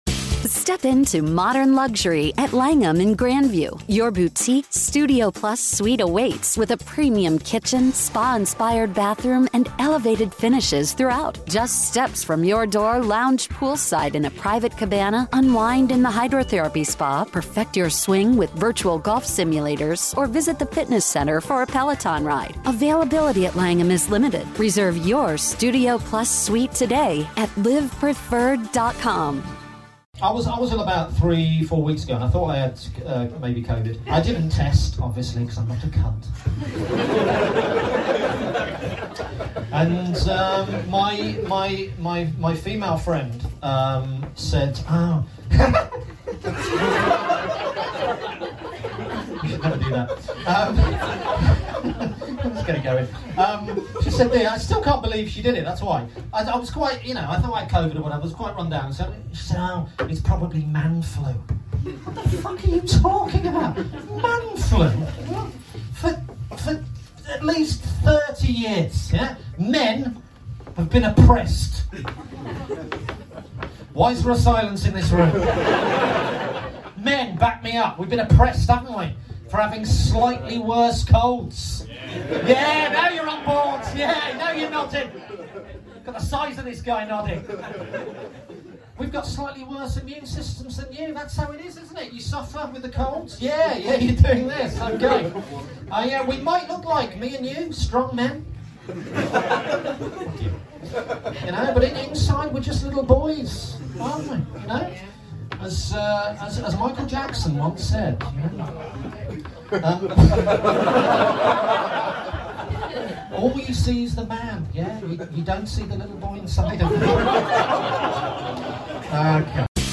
Years of oppression has led us here. Recorded live at Camden Comedy Club November 2023.